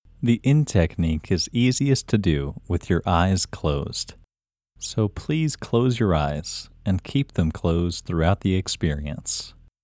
IN – Second Way – English Male 1